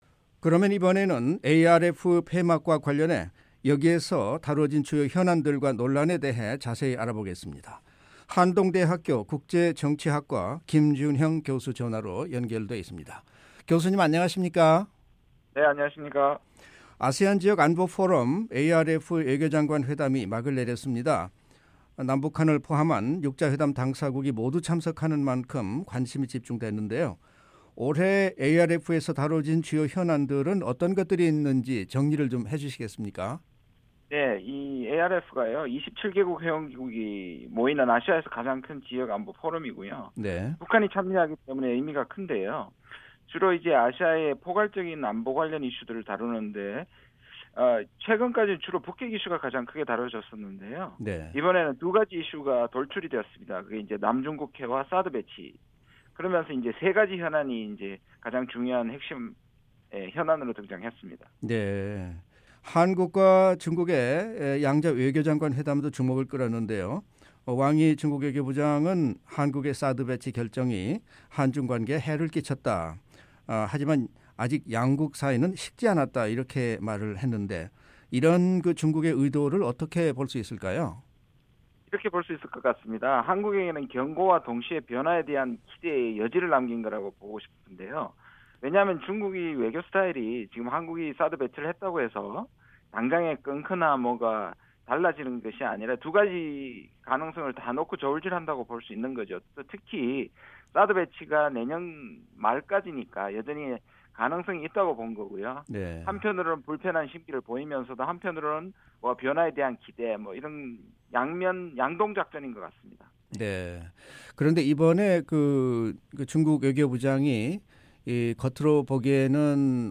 북한이 유일하게 참석하는 지역 다자협의체인 ‘아세안지역안보포럼, ARF’가 폐막했습니다. 이번에 다뤄진 주요 현안과 논란에 대해 한동대학교 국제정치학과 김준형 교수를 전화로 연결해 자세한 이야기 들어보겠습니다.